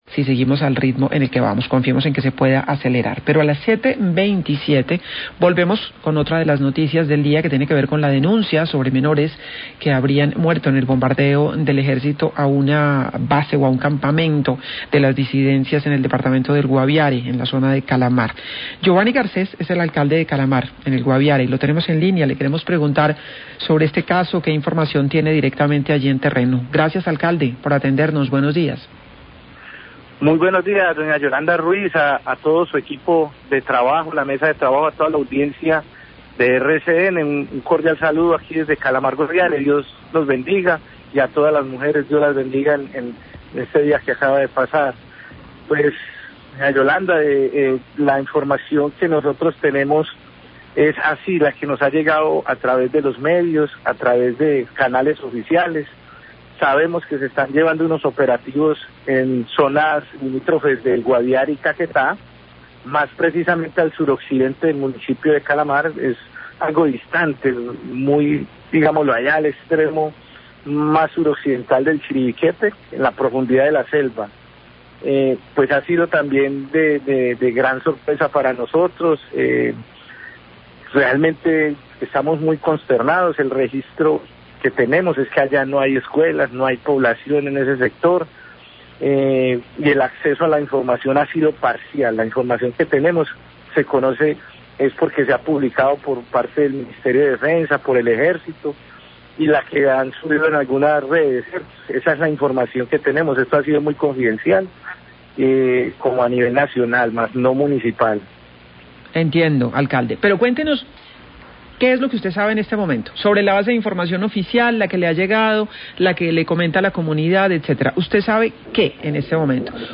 Alcalde de Calamar en Guaviare habla sobre bombardeo a disidencias de FARC y posible muerte de menores
Radio
Alcalde de Calamar, Guaviare, Giovany Garcés, habla sobre las víctimas mortales del bombardeo a Gentil Duarte donde se tiene la información de la muerte de algunos menores de edad. Hable del fenómeno de reclutamiento forzado de menores.